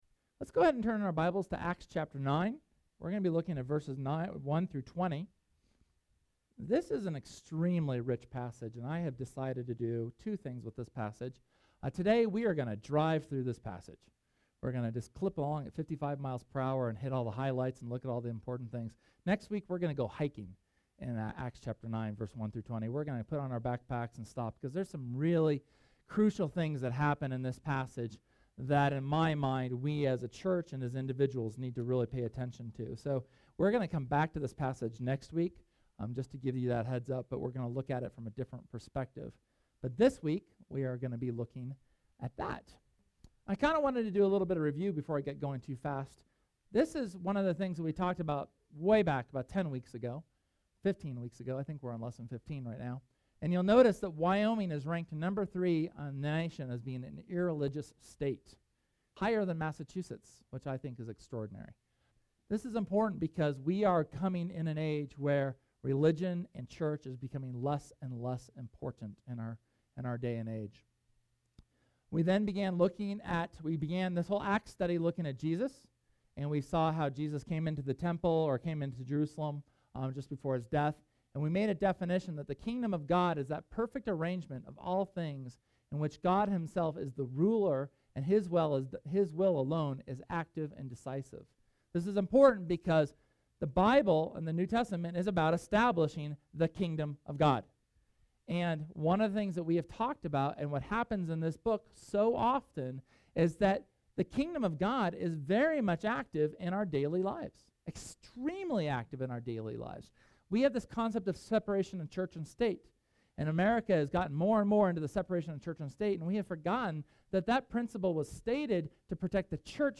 SERMON: Paul’s Damascus Journey – Church of the Resurrection